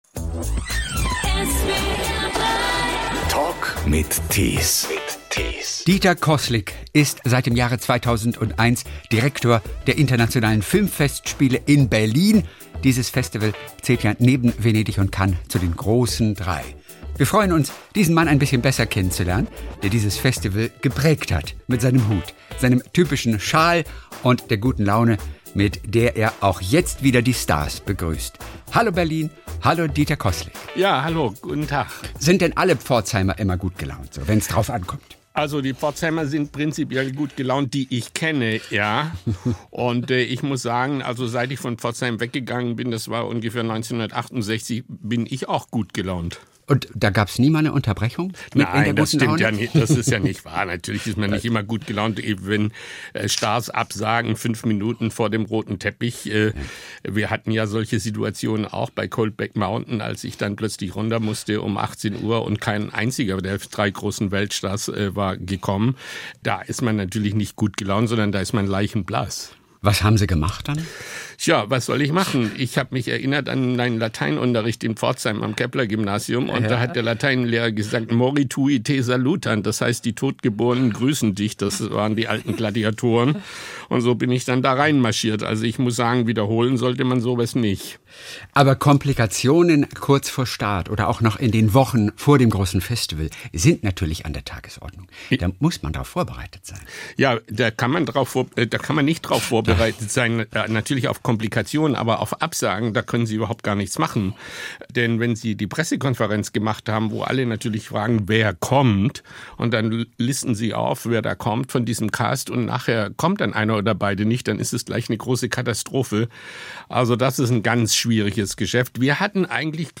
Der Talk in SWR3